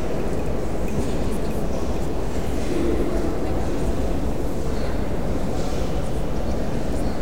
hangar.wav